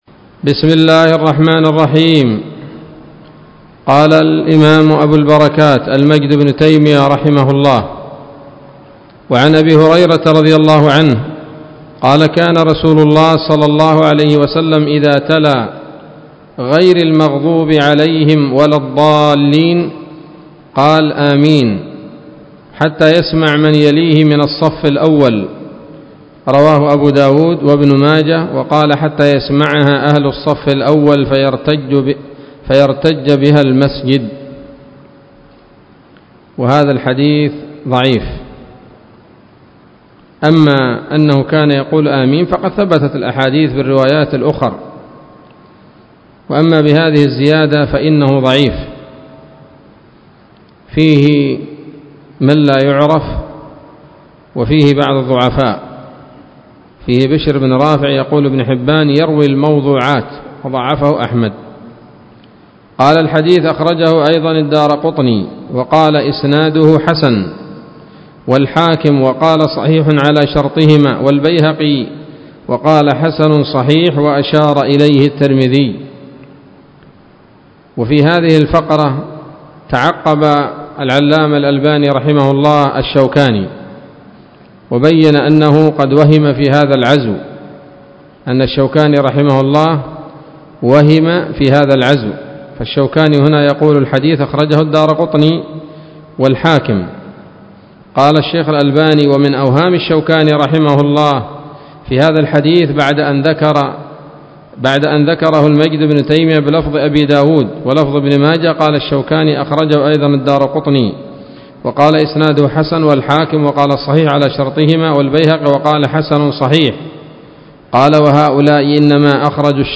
الدرس التاسع والثلاثون من أبواب صفة الصلاة من نيل الأوطار